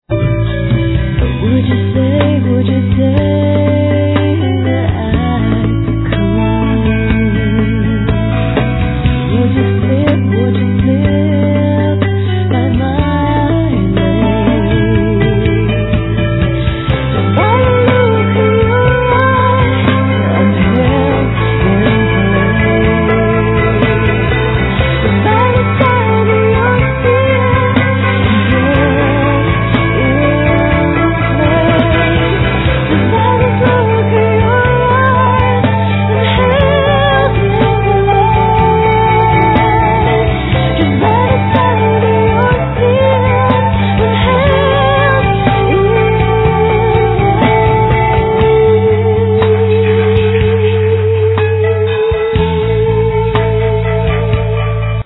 Doumbek, Tar, Riq, Congas, Shakes, Bells
Guitars, Synthes, Piano, Loop, Textures
Vocals
Drum, Loops, Samples
Fender Bass, Synthes, Piano, Shruti box
Trumpet
Flute
Violin
Didgeridoo